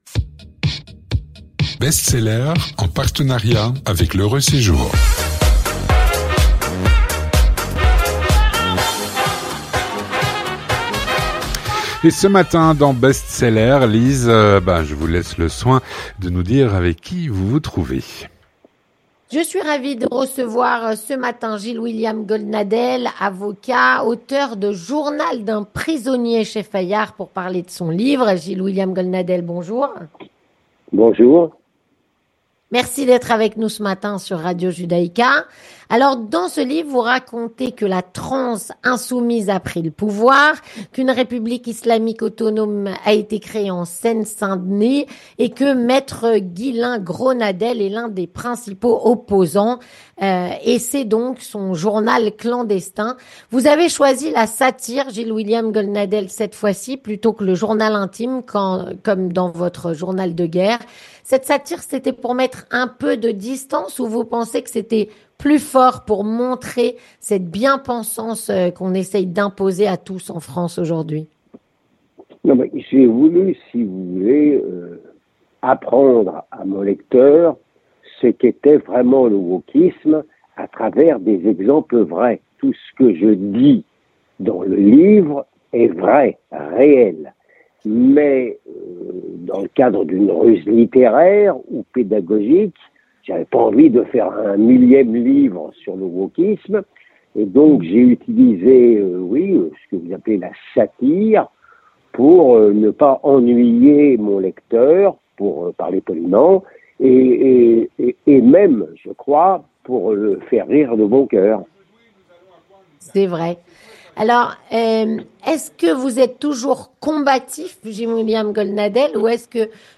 Avec Gilles-William Goldnadel, Avocat, essayiste et auteur de cet ouvrage.
Il répond aux questions